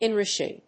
アクセント・音節ín・rùsh・ing